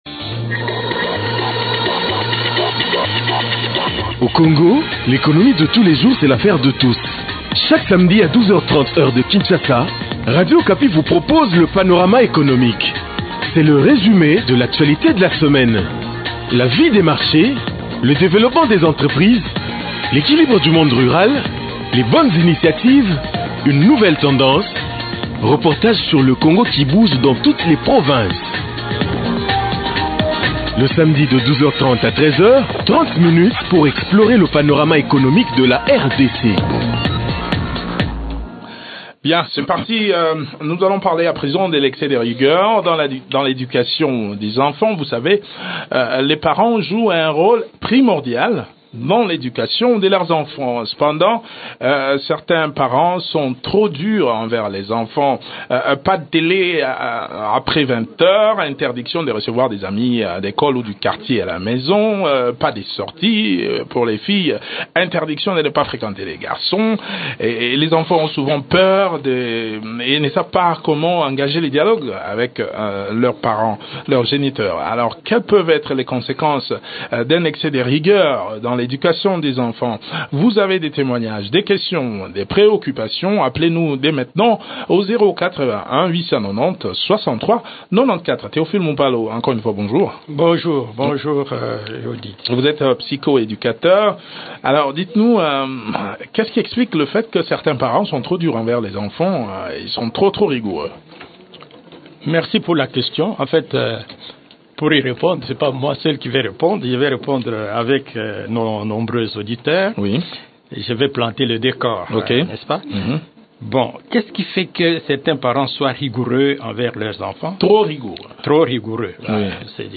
psycho-éducateur.